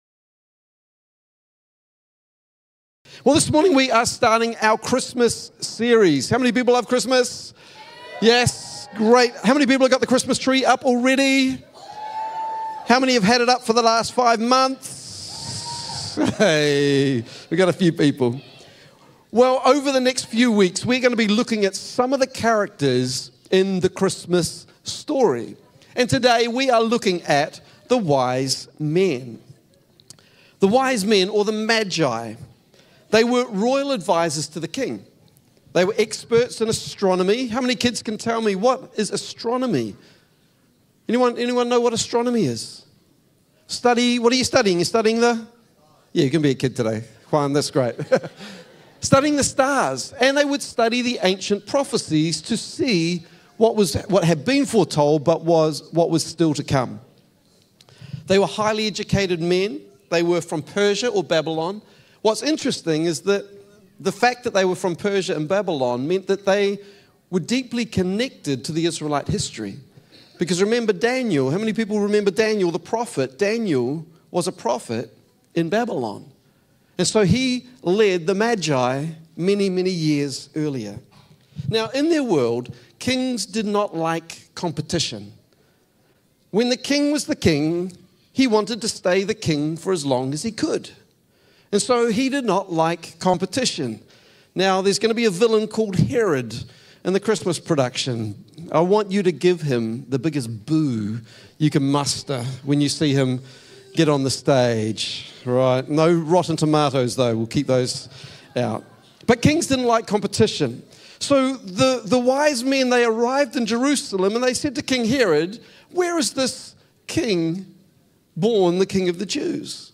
Rediscover Church Exeter | Sunday Messages